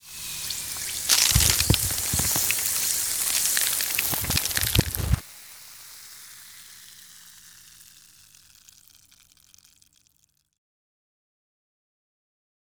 dissolve.wav